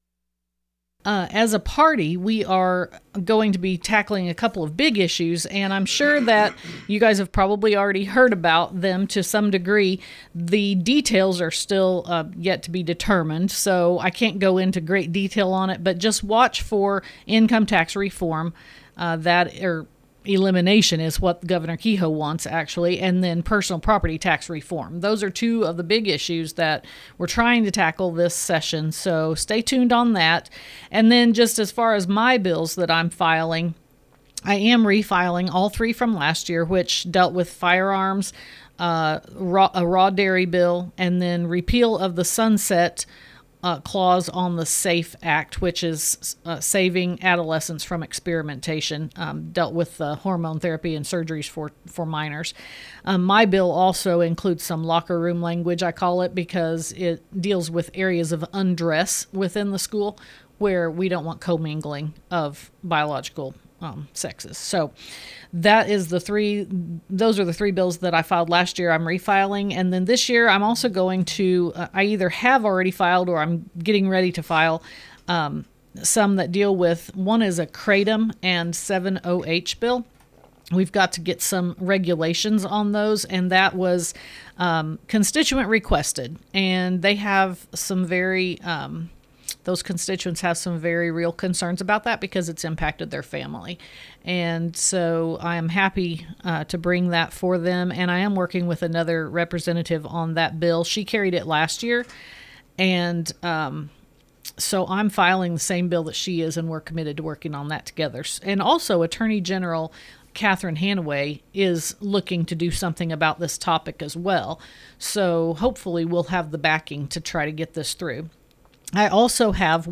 West Plains, MO. – Last Friday, Lisa Durnell, Missouri Representative of the 154th District, stepped into the studio to discuss her year in-office during 2025, and what she expects our of 2026.